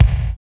27.05 KICK.wav